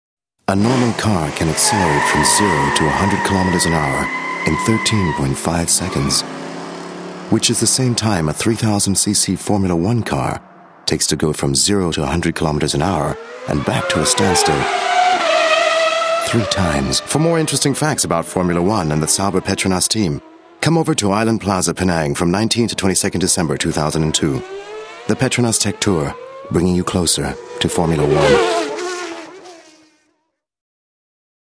English, low, husky story telling, documentaries,presentations and commercial announcer
Sprechprobe: Sonstiges (Muttersprache):